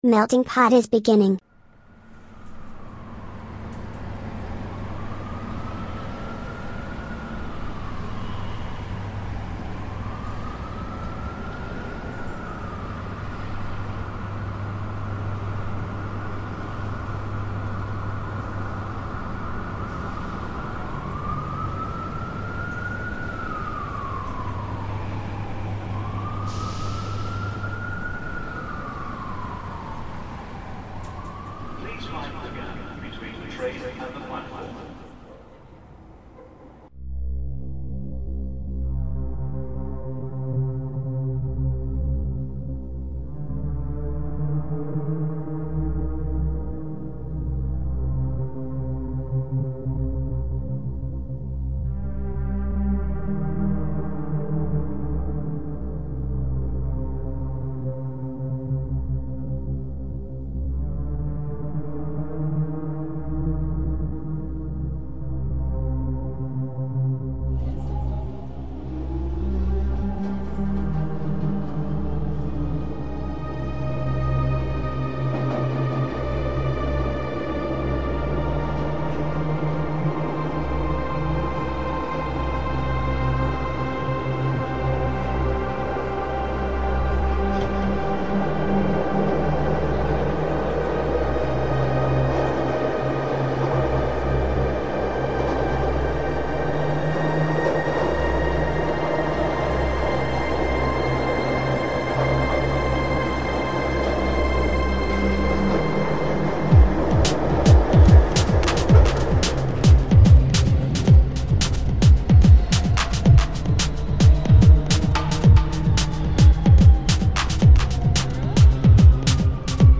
MUSICA E NOTIZIE A MELTINGPOT | Radio Città Aperta